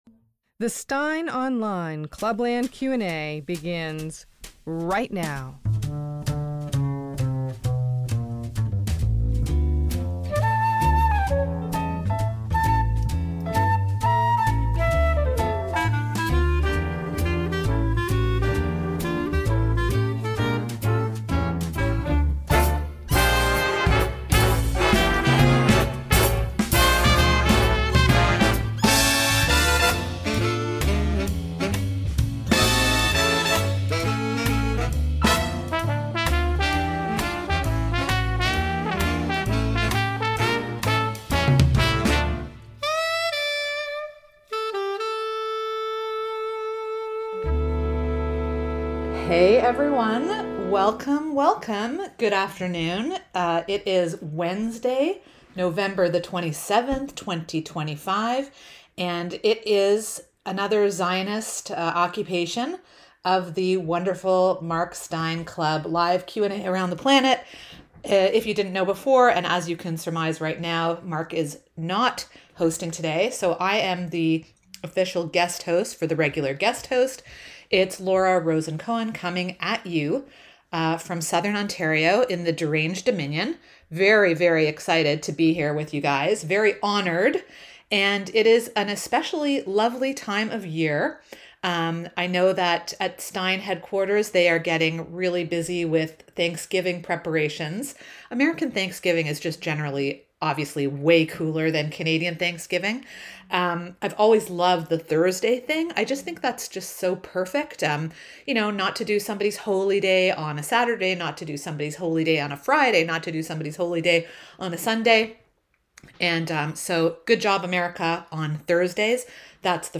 If you missed today's Clubland Q&A live around the planet, here's the action replay.